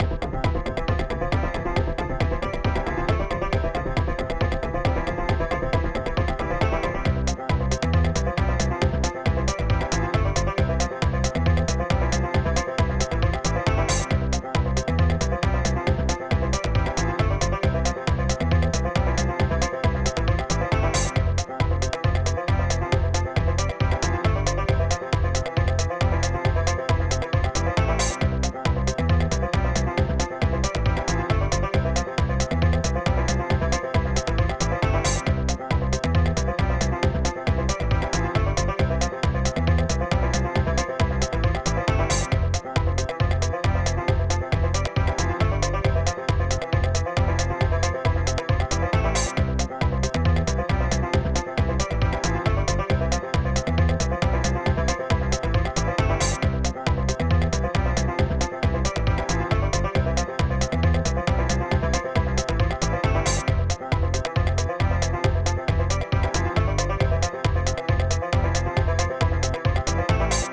mod (ProTracker MOD (6CHN))